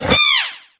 Cri de Flambino dans Pokémon HOME.